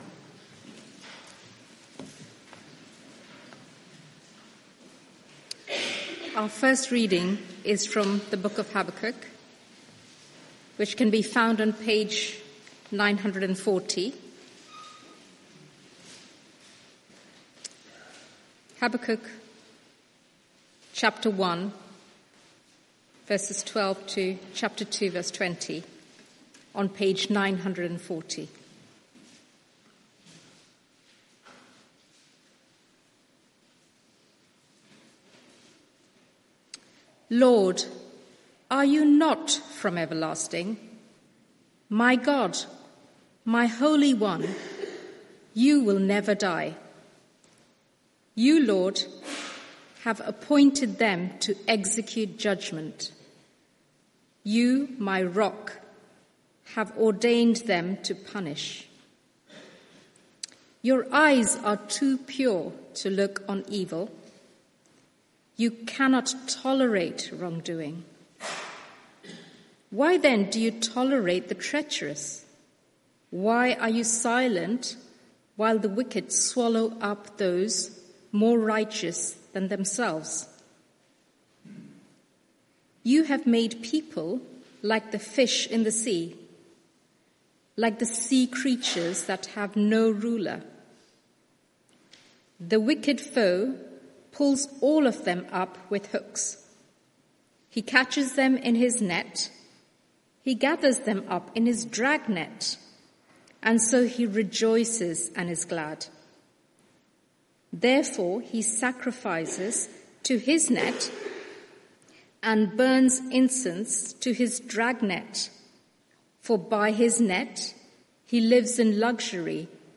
Media for 6:30pm Service on Sun 15th Jun 2025 18:30 Speaker
Sermon (audio) Search the media library There are recordings here going back several years.